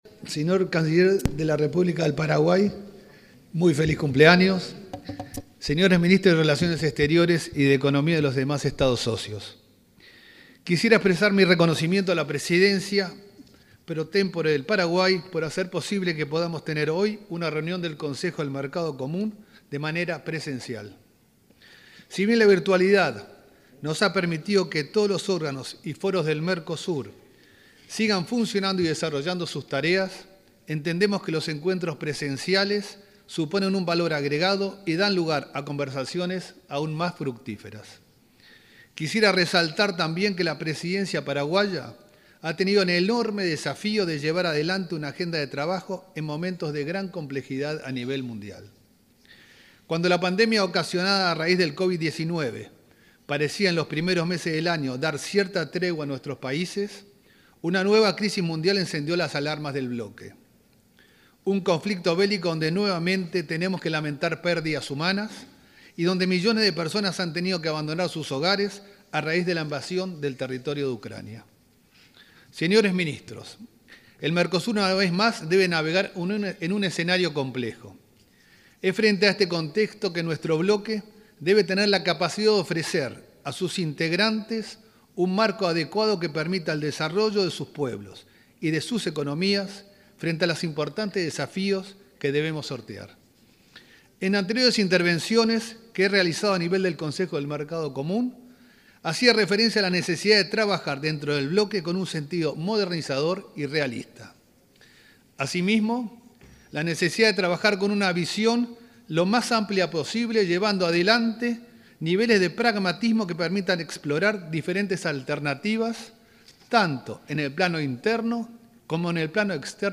Palabras del canciller Francisco Bustillo
Palabras del canciller Francisco Bustillo 20/07/2022 Compartir Facebook X Copiar enlace WhatsApp LinkedIn El ministro de Relaciones Exteriores, Francisco Bustillo, disertó este miércoles 20 en Paraguay, en la reunión de cancilleres que se lleva a cabo en el marco de la Cumbre del Mercosur de este jueves 21.